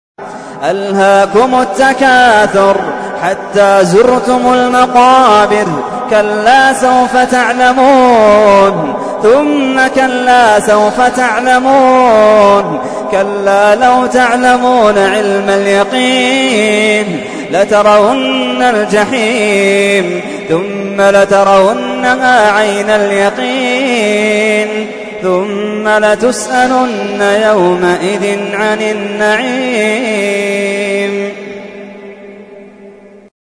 تحميل : 102. سورة التكاثر / القارئ محمد اللحيدان / القرآن الكريم / موقع يا حسين